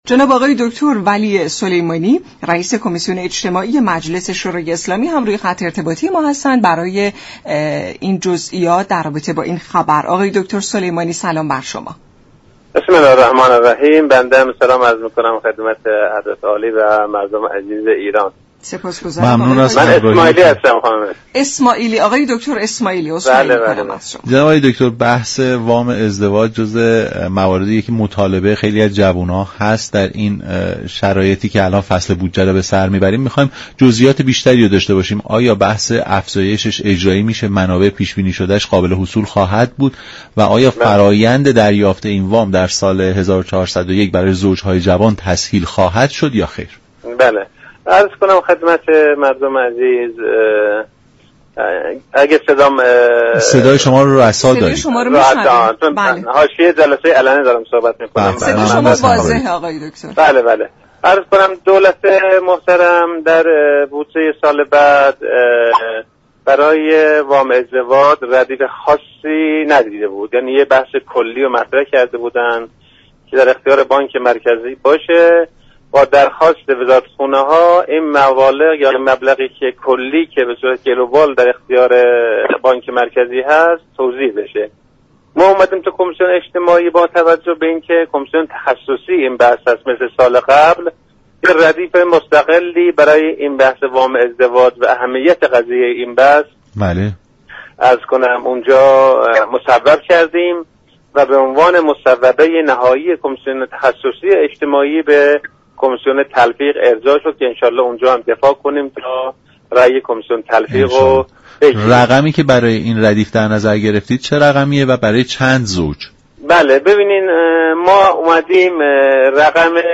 به گزارش شبكه رادیویی ایران، ولی اسماعیلی رییس كمیسیون اجتماعی مجلس در برنامه نمودار درباره جزئیات طرح افزایش وام ازدواج گفت: از آنجا كه دولت در بودجه سال 1401 برای وام ازدواج ردیف خاصی لحاظ نكرده بود كمیسیون اجتماعی مجلس برای این موضوع، ردیف مستقلی مصوب كرده و در قالب مصوبه تخصصی به كمیسیون تلفیق ارجاع داده است.